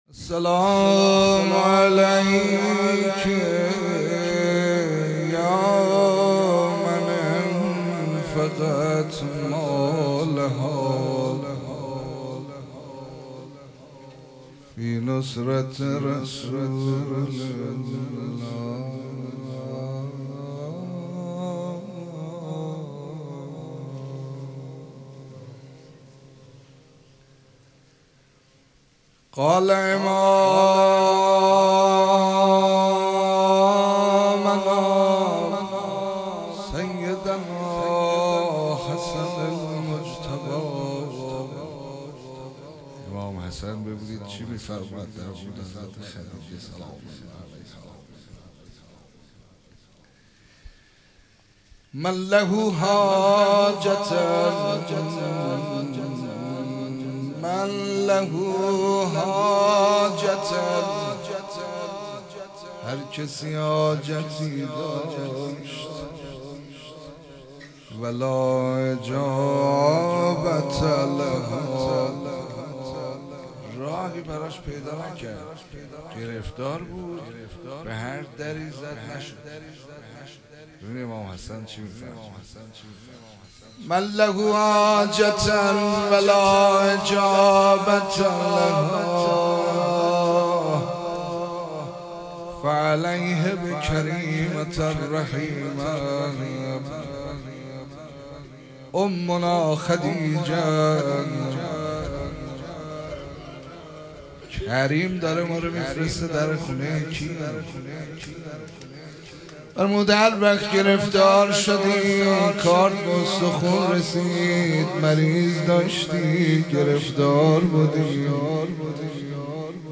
شهادت حضرت خدیجه علیها سلام - روضه